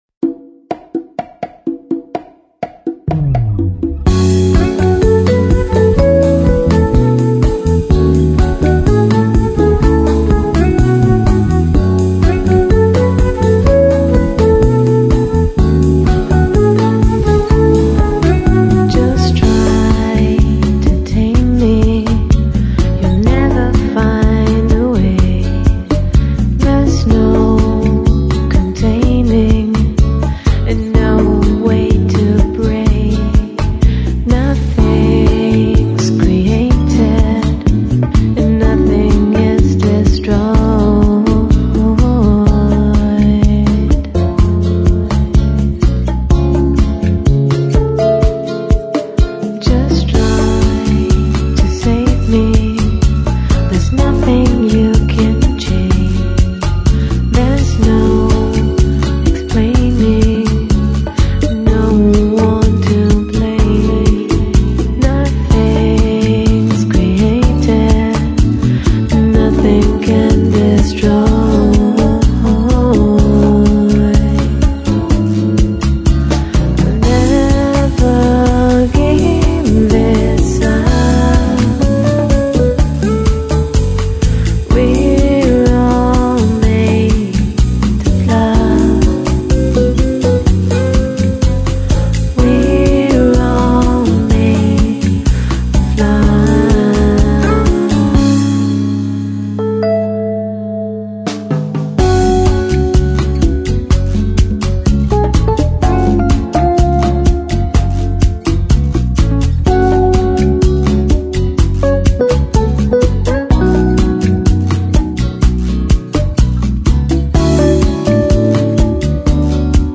Bossa